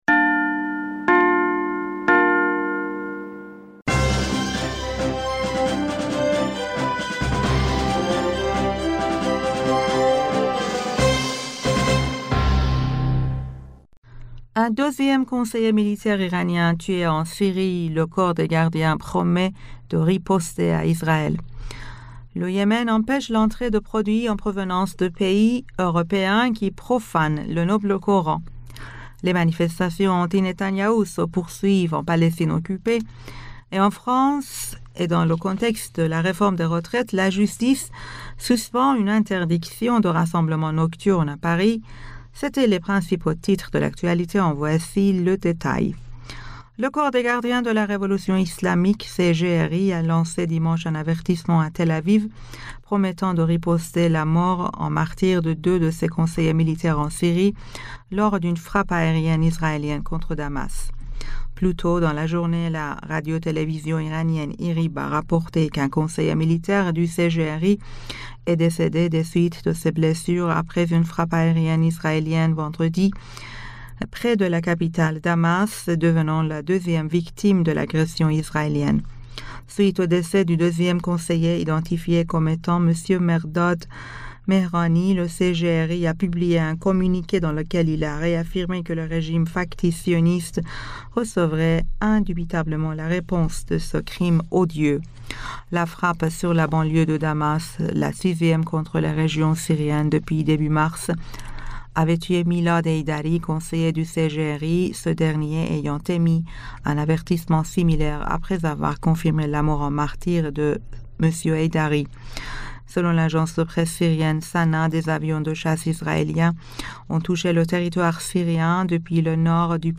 Bulletin d'information du 02 Avril 2023